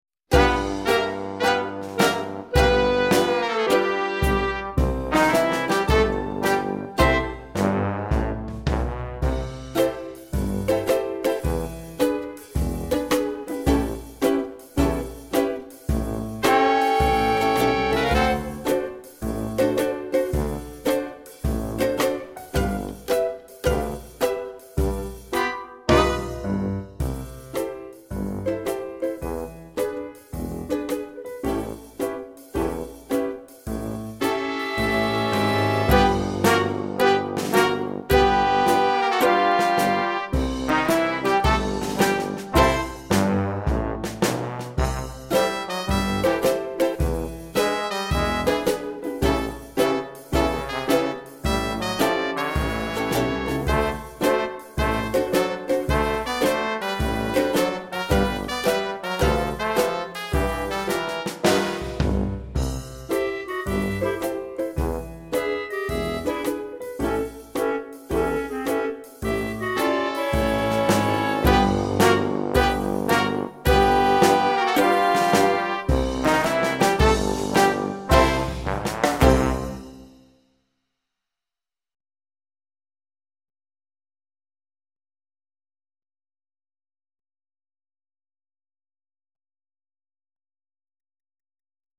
27 Ukukele Sam (Backing Track)